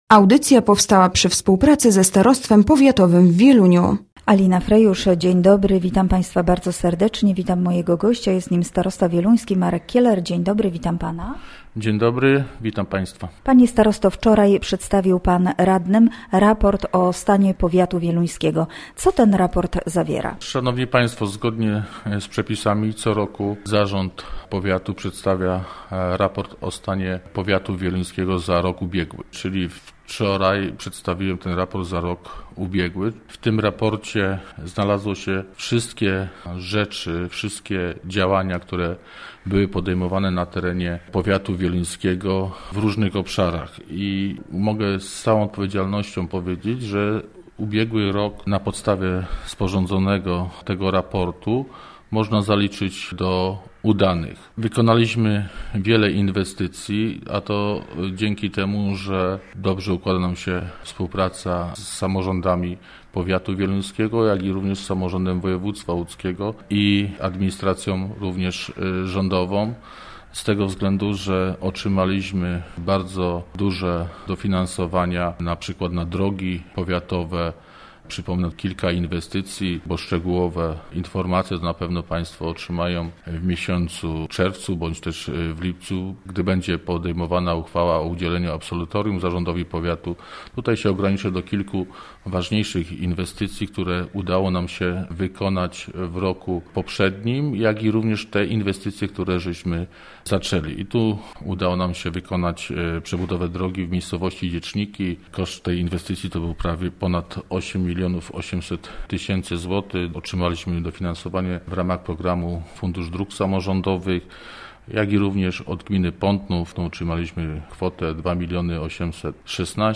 Gościem Radia ZW był Marek Kieler, starosta powiatu wieluńskiego